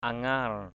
/a-ŋa:r/ (d.) thứ ba = mardi. Tuesday.